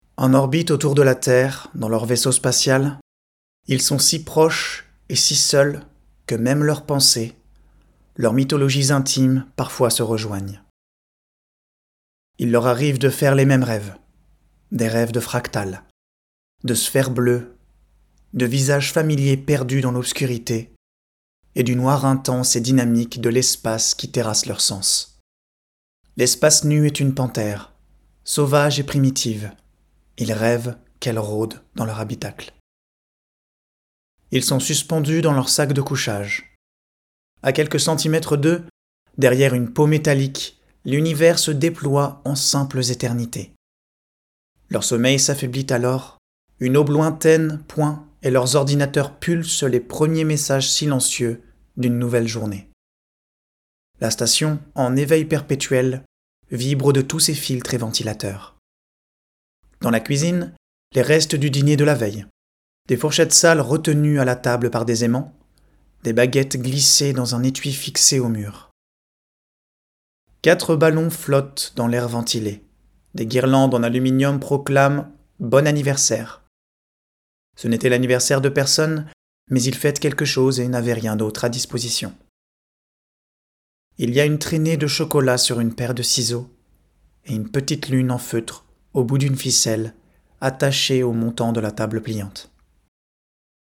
Voix off
Extrait narratif - prononcé, dynamique
10 - 35 ans - Contre-ténor